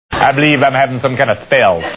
Dave in his sissy voice: "I believe I'm having some kind of spell!"."